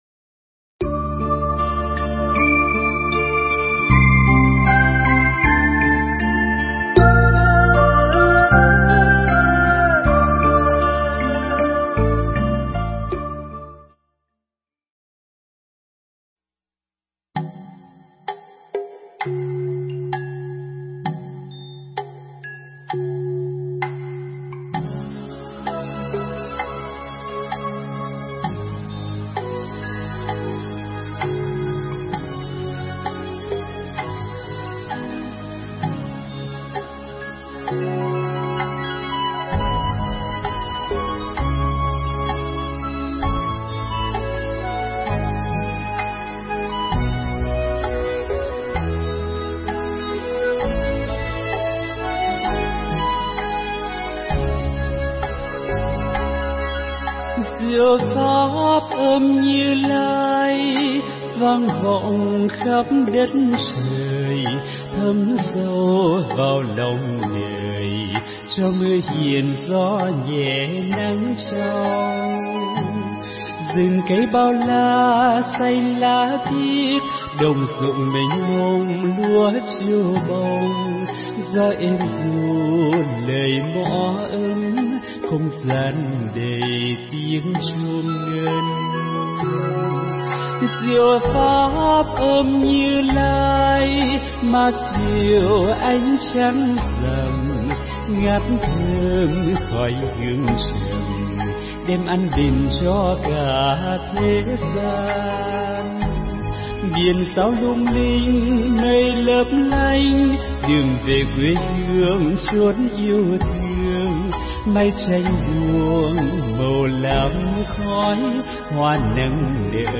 Mp3 Thuyết pháp Chứng Đạt Chân Lý